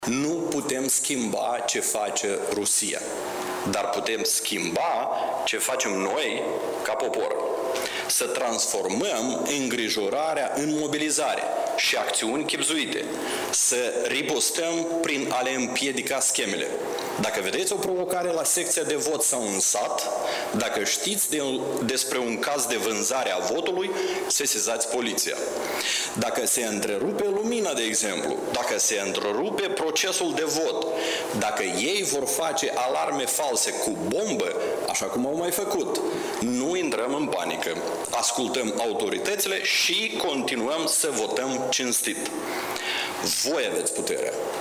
„Presiunea devine tot mai mare, iar probele în ceea ce privește acțiunile subversive ale Rusiei sunt tot mai numeroase”, a spus premierul Dorin Recean într-o conferință de presă.